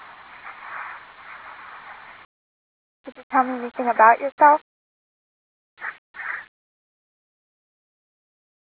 Salmon Cemetery EVP's
and then a woman's voice telling him how it is!